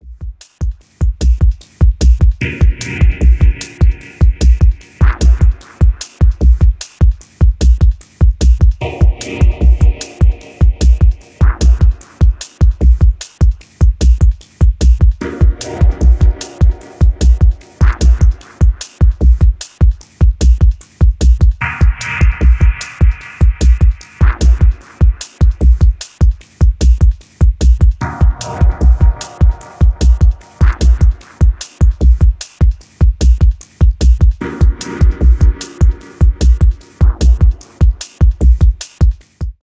Sample based, uptempo experiments.